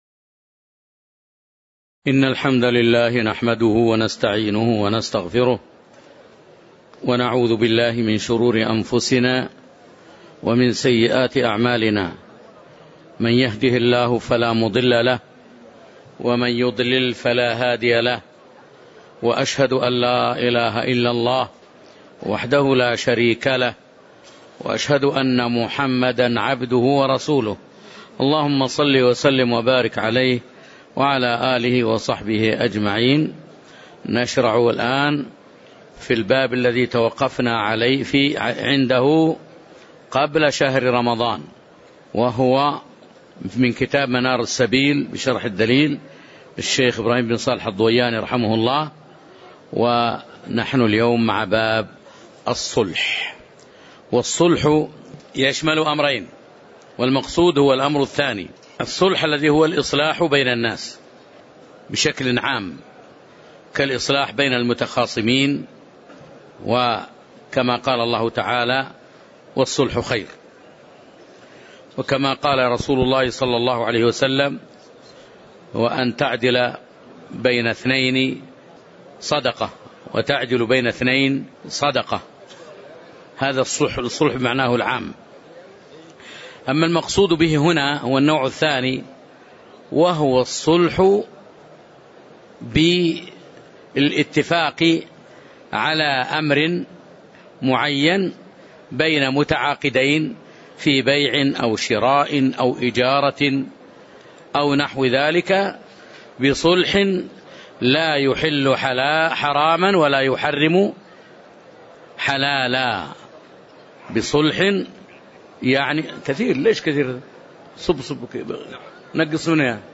تاريخ النشر ١٣ محرم ١٤٤١ هـ المكان: المسجد النبوي الشيخ